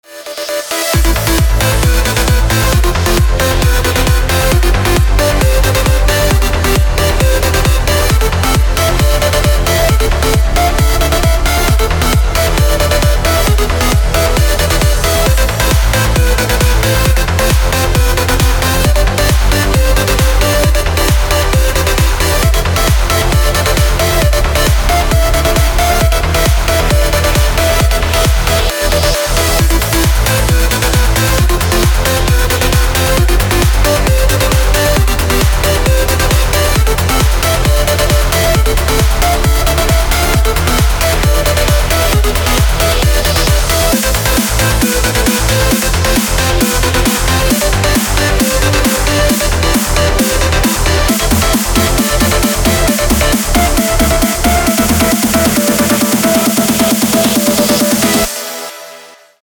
• Качество: 256, Stereo
красивые
dance
Electronic
электронная музыка
без слов
club
Trance